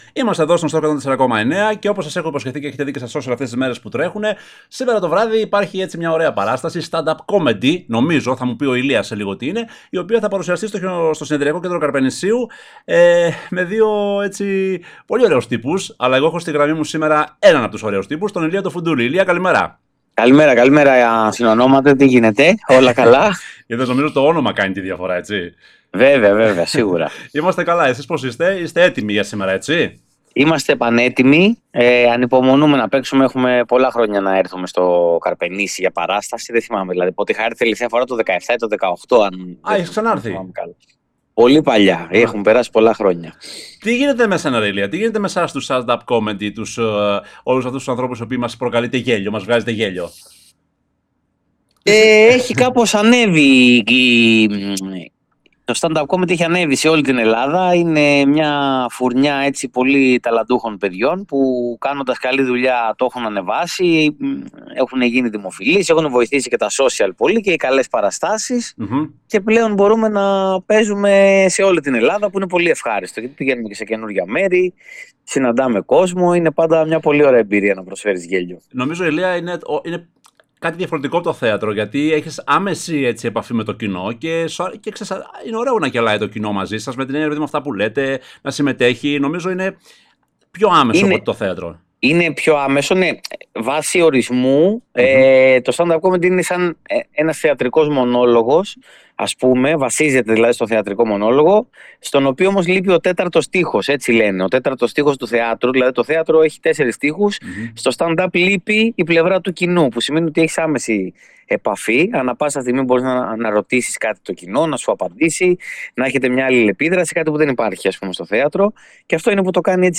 αποκλειστική συνέντεξη στον Stoxos 104.9